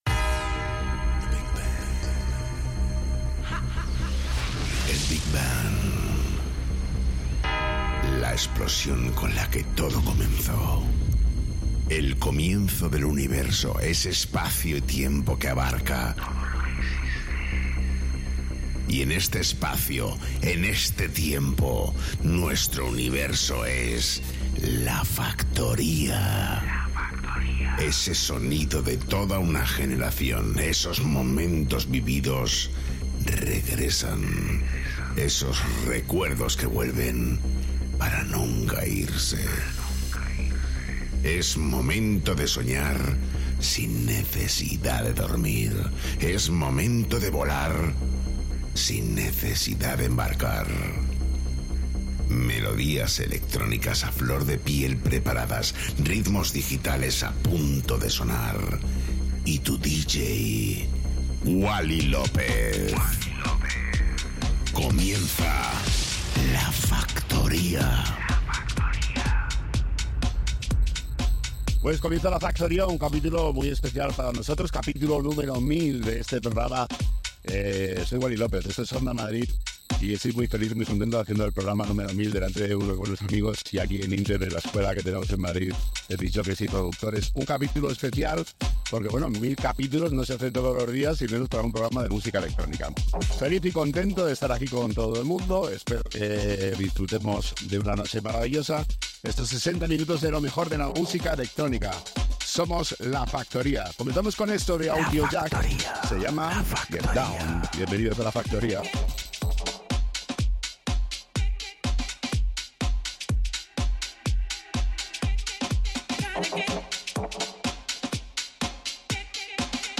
Wally López, el DJ más internacional de Madrid retoma La Factoría para todos los madrileños a través de Onda Madrid.